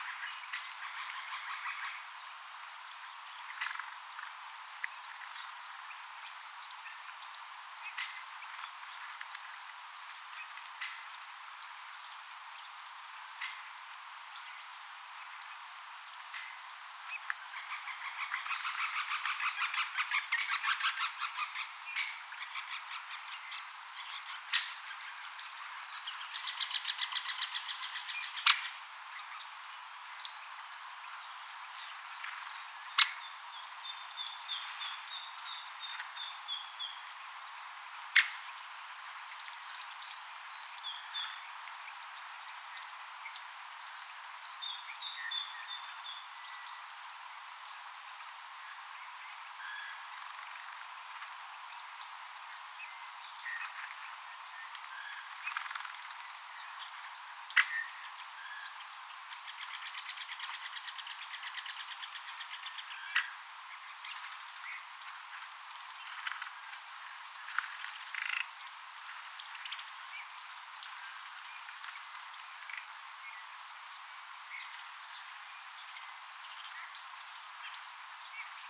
käpytikkojen keväistä ääntelyä
Tällaista kuulee lähinnä keväisin,
kapytikkojen_aatelya_sellaista_mita_kuulee_kevaalla.mp3